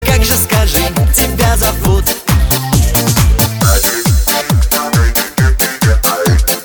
• Качество: 320, Stereo
громкие